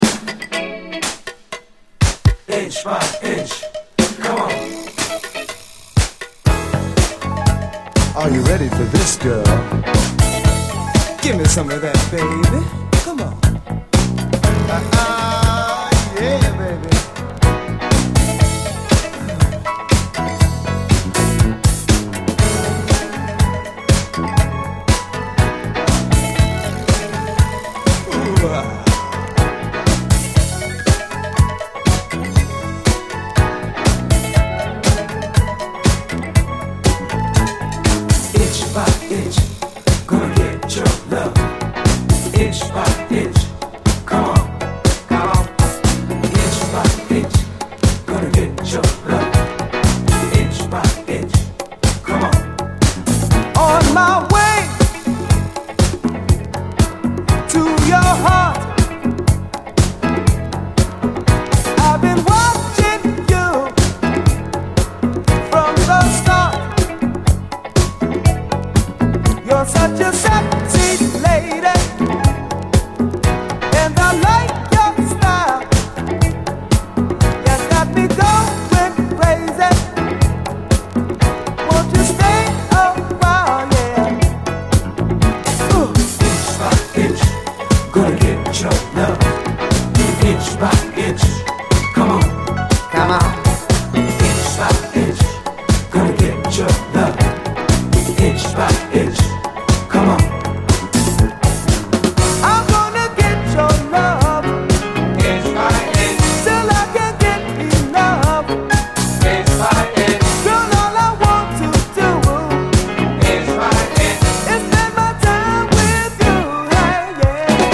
幻想的かつトロピカルなシンセ・ワークが主張する、先鋭ダビー・メロウ・ディスコ群！